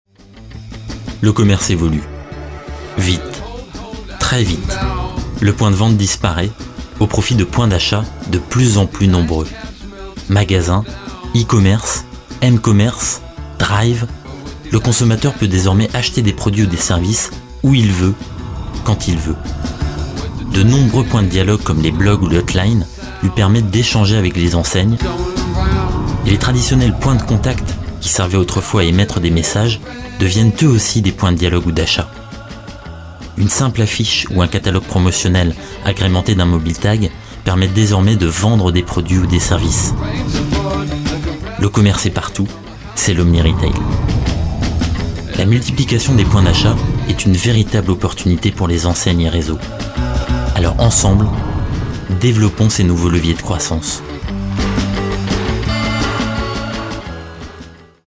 Voix off homme grave institutionnel tv
Sprechprobe: Industrie (Muttersprache):
Voice over man medium bass tv